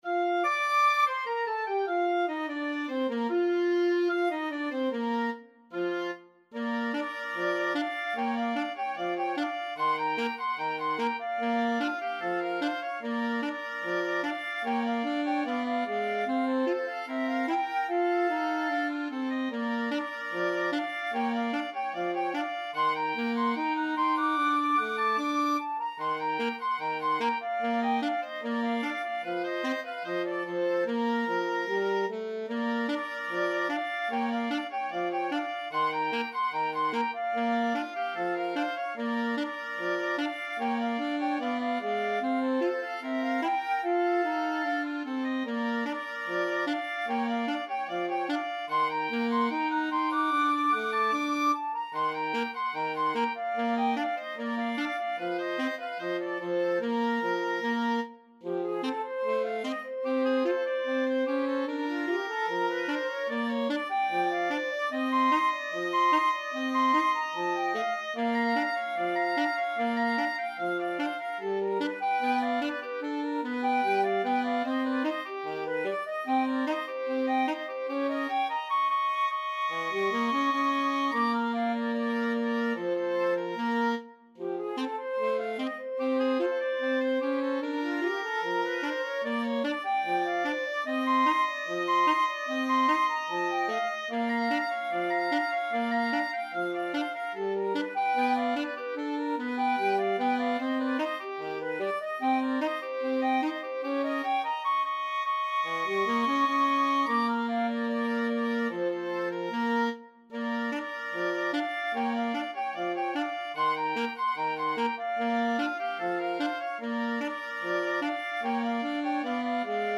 Free Sheet music for Flexible Mixed Ensemble - 3 Players
FluteClarinet
OboeClarinet
Alto SaxophoneClarinet
Bb major (Sounding Pitch) (View more Bb major Music for Flexible Mixed Ensemble - 3 Players )
Not Fast = 74
2/4 (View more 2/4 Music)
Jazz (View more Jazz Flexible Mixed Ensemble - 3 Players Music)